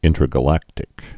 (ĭntrə-gə-lăktĭk)